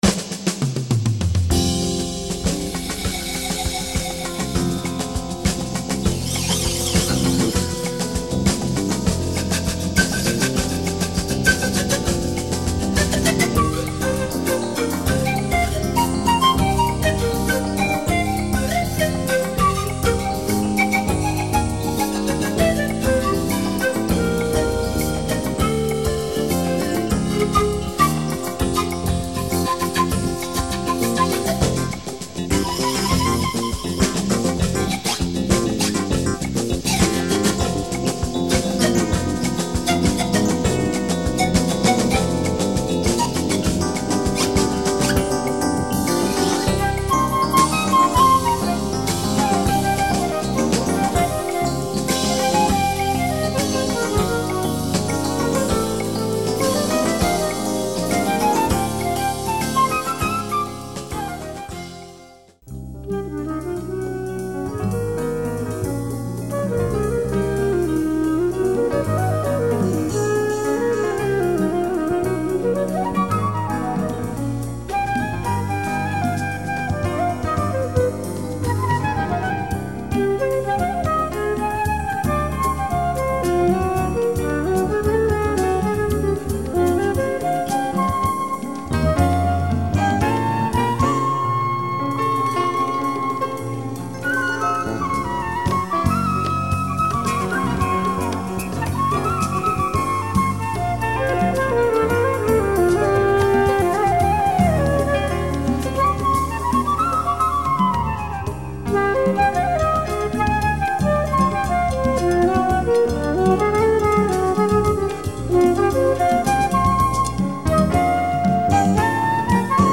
Fusion jazz folk and candombe from Argentina.